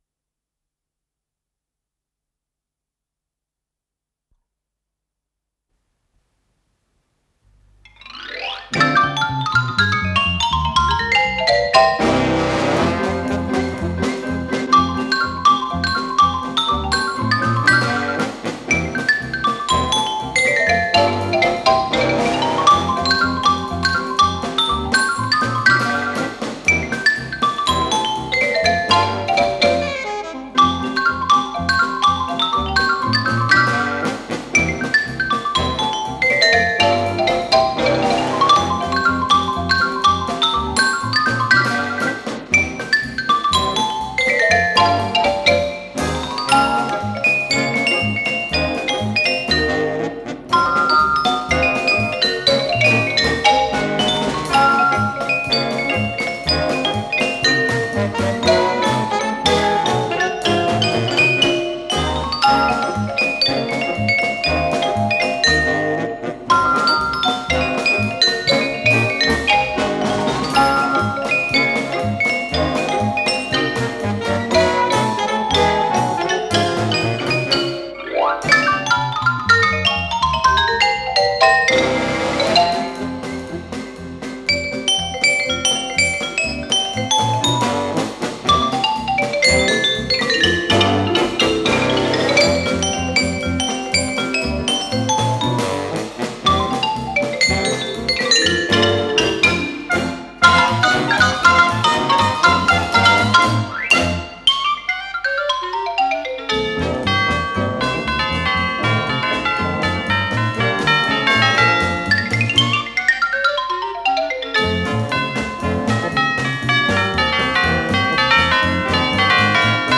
с профессиональной магнитной ленты
маримбафон
вибрафон, колокола